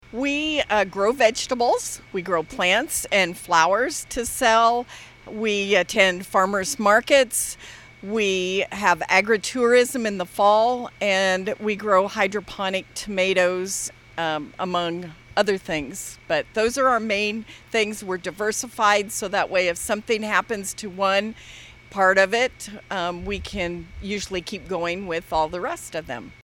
in one of their greenhouses last month